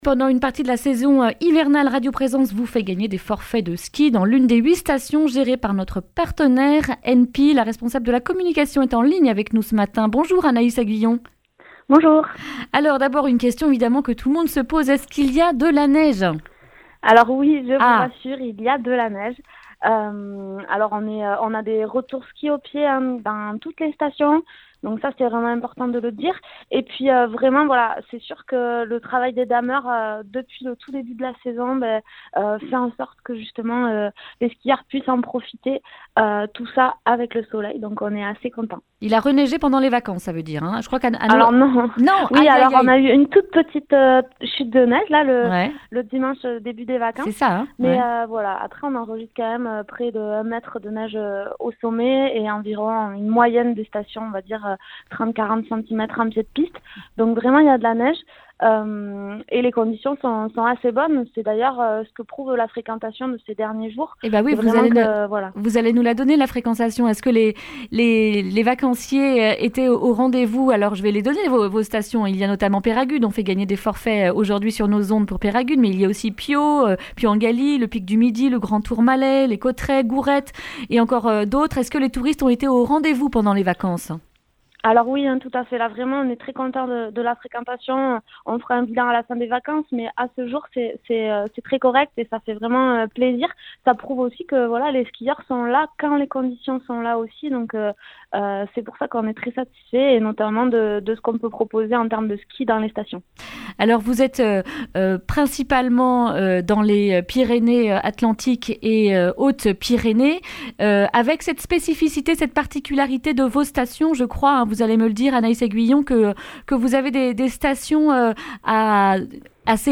jeudi 2 janvier 2020 Le grand entretien Durée 10 min